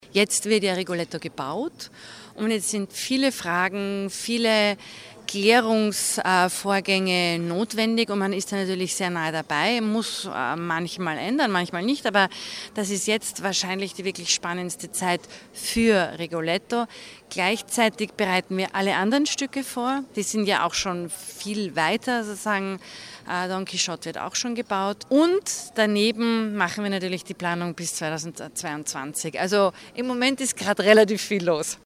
PK Festspielprogramm 2019 O- Töne News